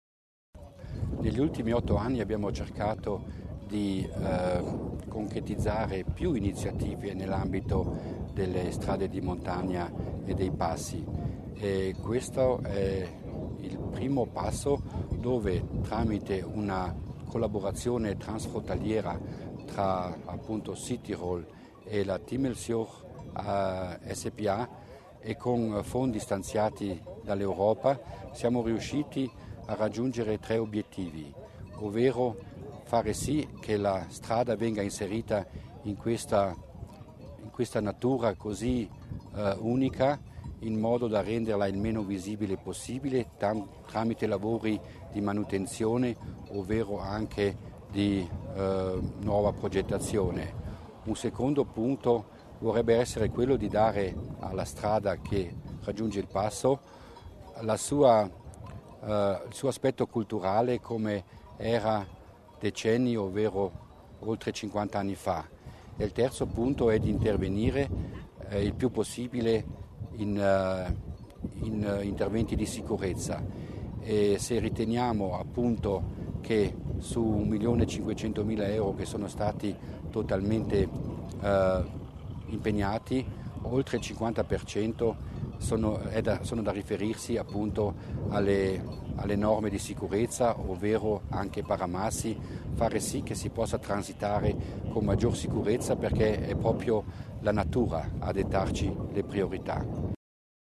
L¿Assessore Mussner sull'importanza del progetto